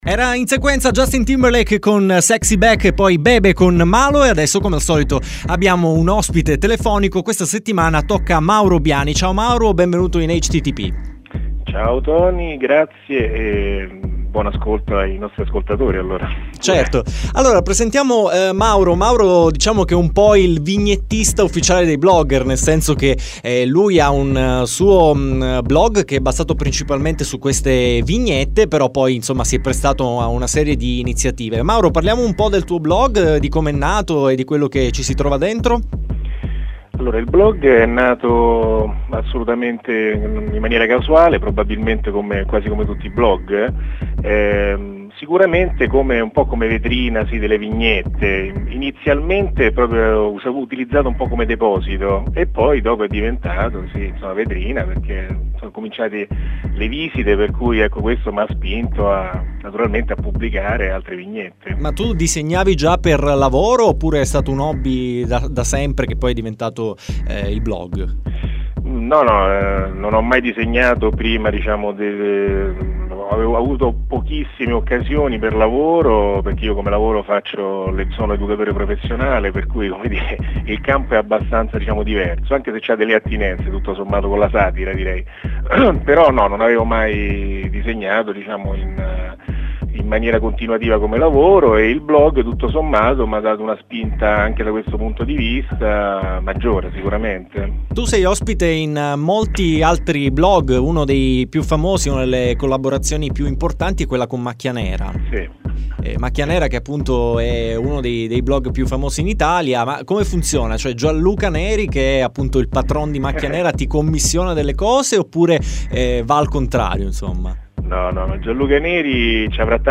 Intervista citata